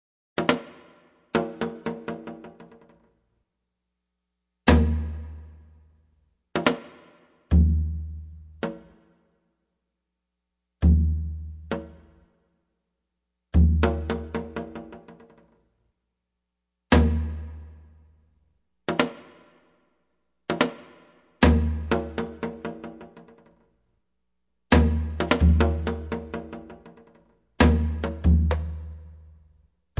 Electric Changgo permutations